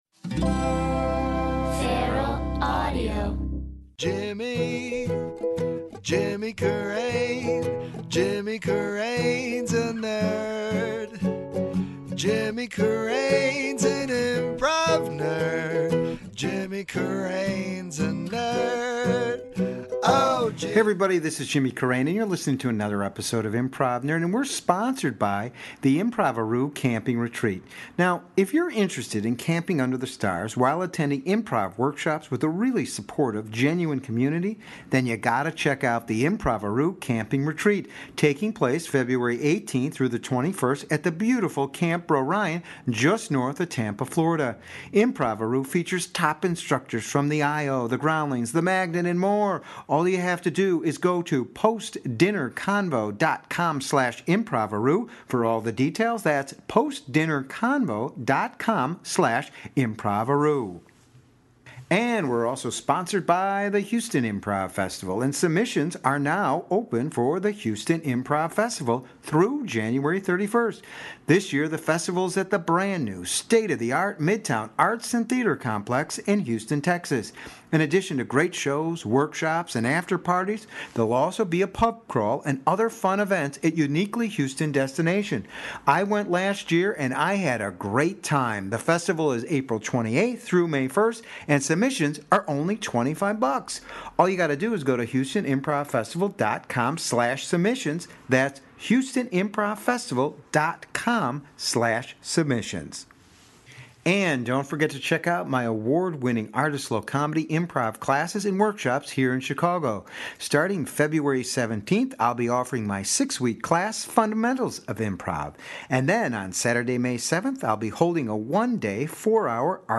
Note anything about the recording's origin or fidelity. In this lively episode recorded at the Institution Theater in Austin